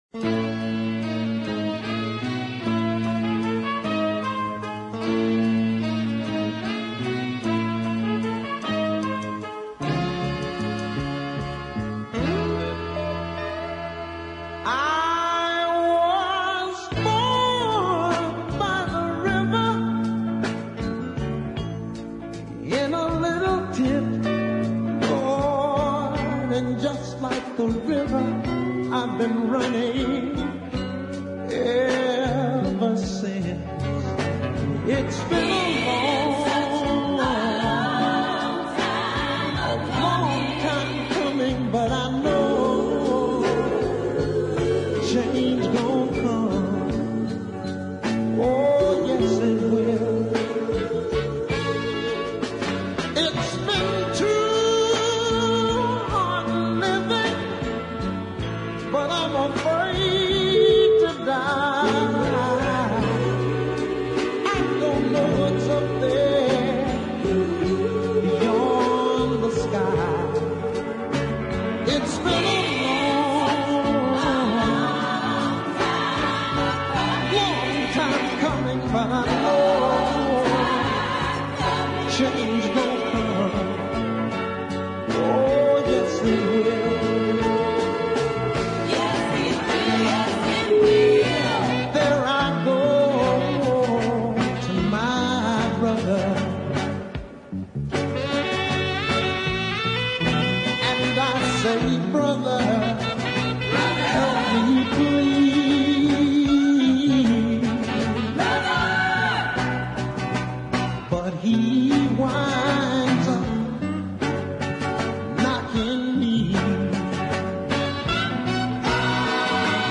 lovely horn chart and solo sax